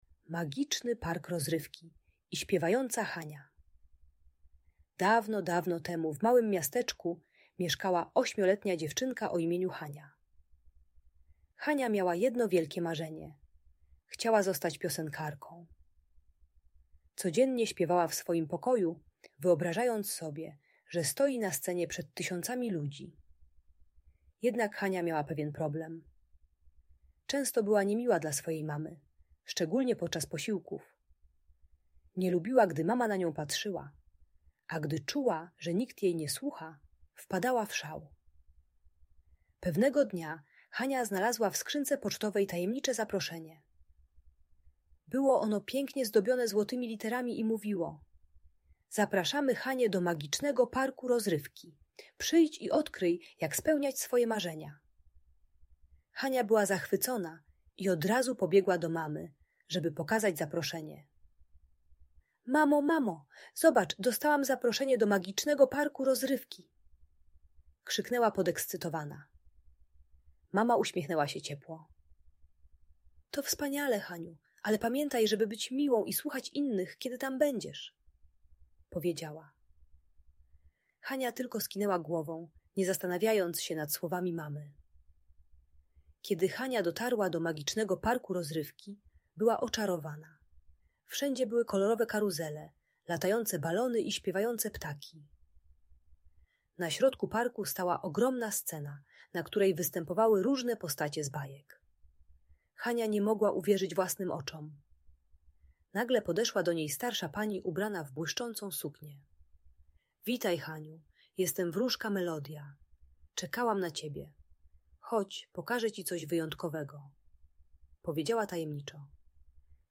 Magiczny Park Rozrywki: Historia o Śpiewającej Hani - Audiobajka